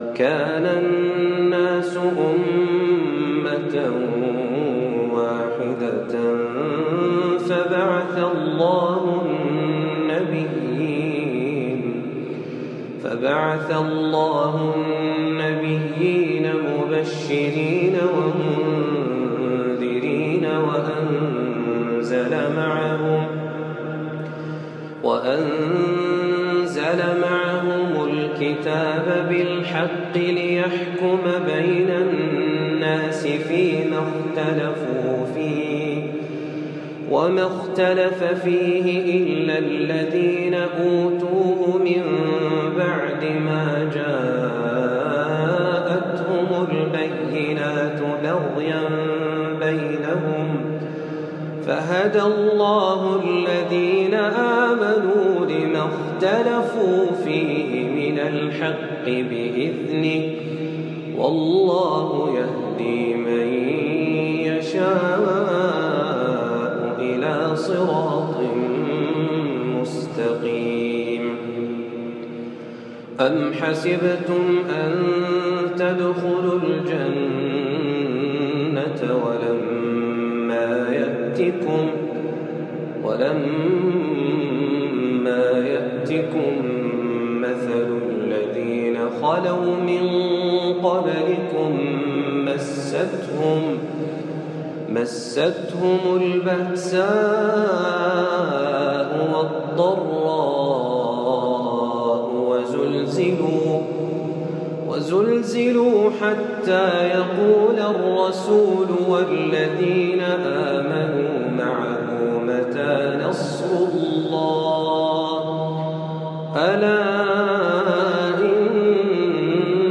جديد - استمعوا إلى هذه التلاوة الخاشعة
تلاوة خاشعة من سورة البقرة